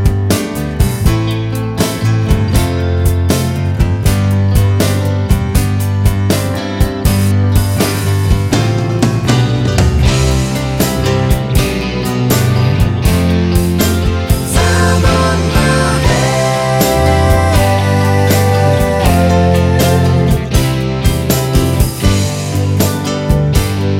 Minus Harmonica Pop (1970s) 4:42 Buy £1.50